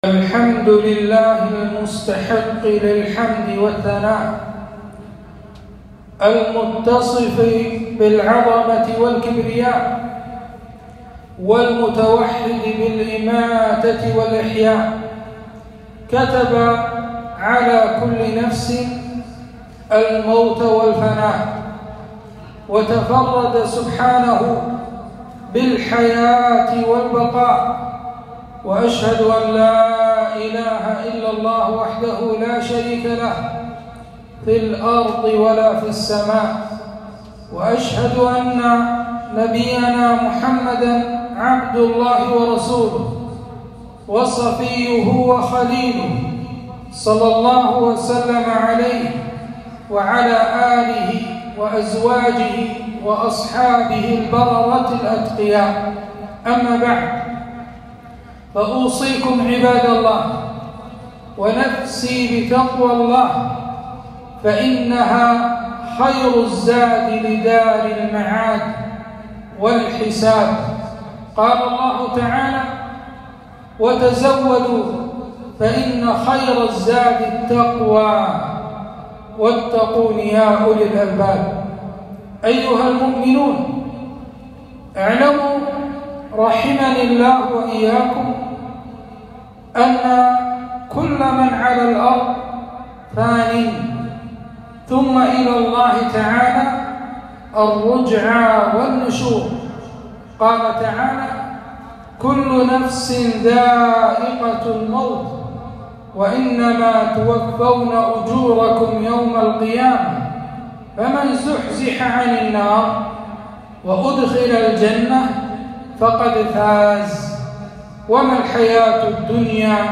خطبة - فاجعة رحيل الأمير صباح الأحمد الجابرالصباح-رحمه الله-